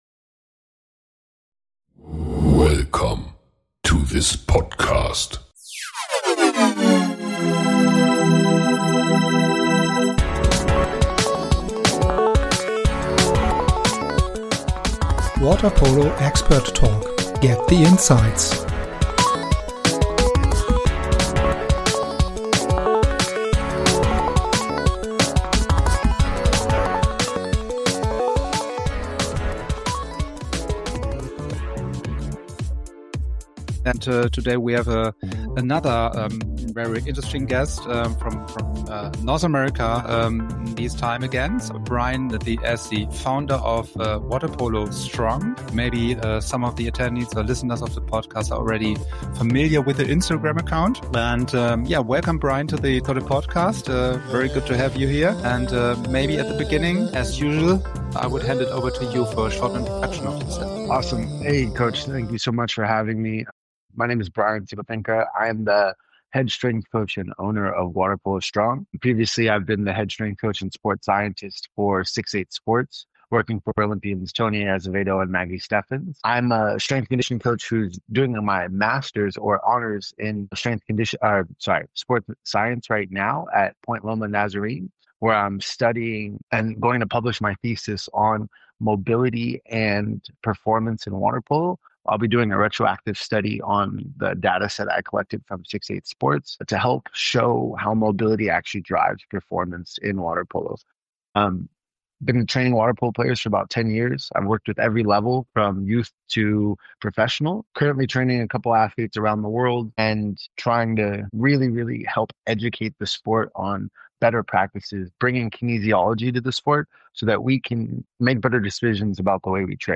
The conversation further touches on hydration and in-game fueling, why athletes lose far more fluids and carbohydrates than they realize, and how modern sports nutrition is starting to influence elite water polo performance.